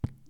water-bup
bath bathroom bathtub bubble bup burp drain drip sound effect free sound royalty free Nature